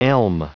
Prononciation du mot elm en anglais (fichier audio)
Prononciation du mot : elm